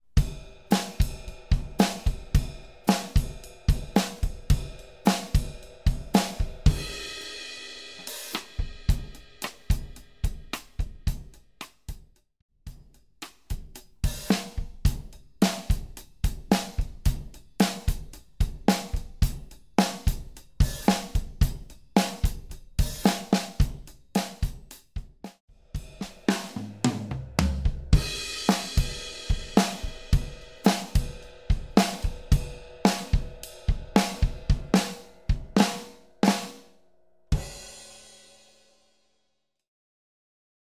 BPM 100
Style Rock, Roots Time Sig 4/4